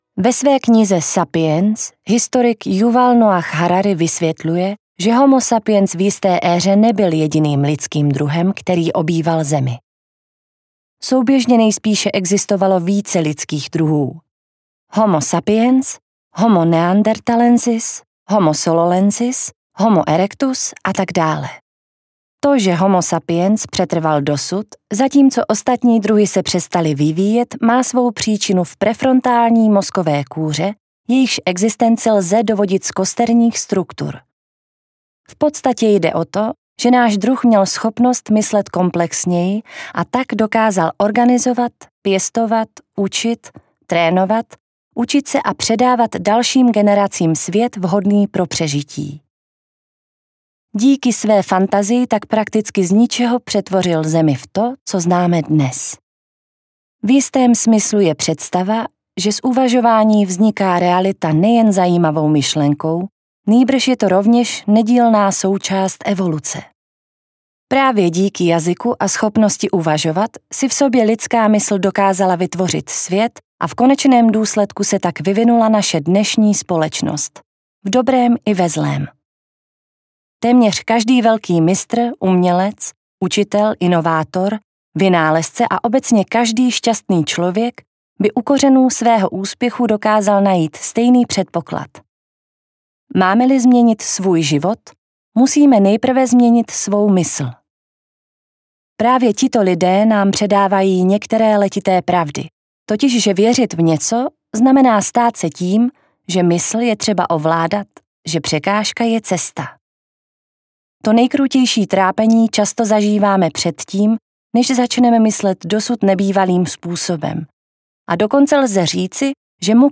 101 esejů, které změní vaše myšlení audiokniha
Ukázka z knihy